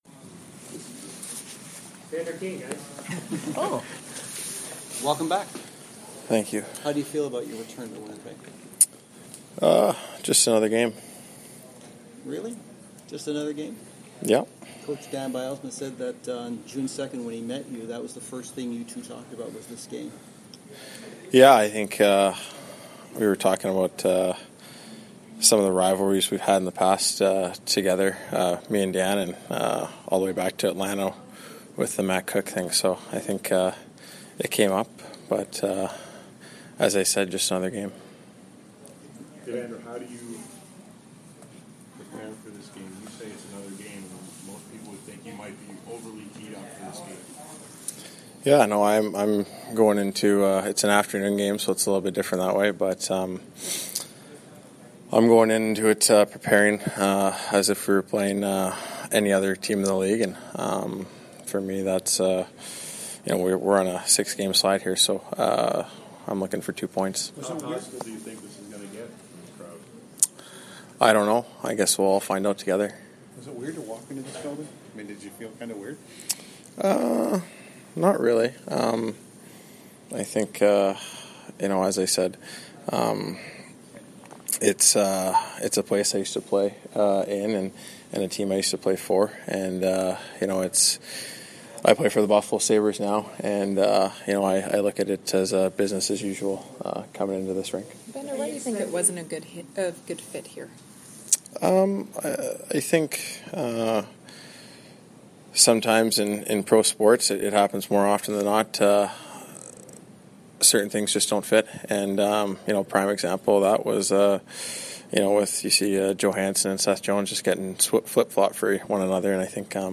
Zach Bogosian and Evander Kane scrums.
The two former Jets met with the media following skate on the visitors side of the rink.